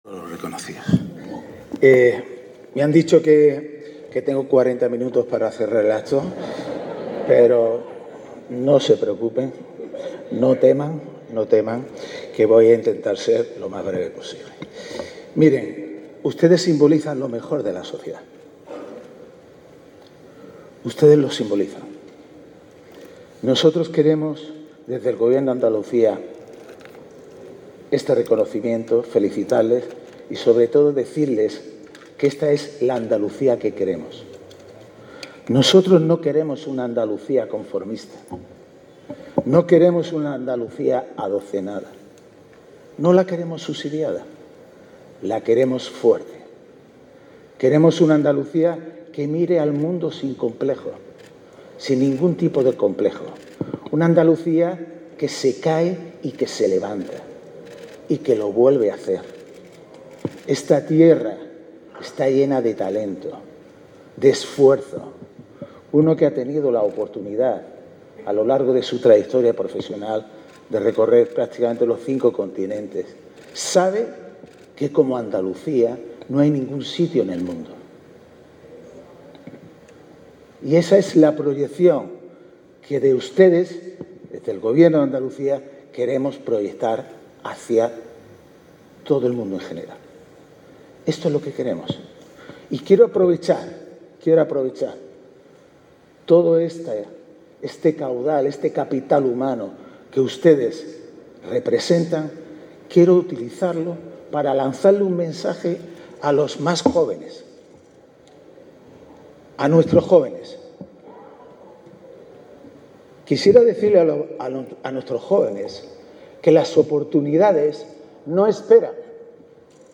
Imbroda-clausura-acto-Andalucia-.mp3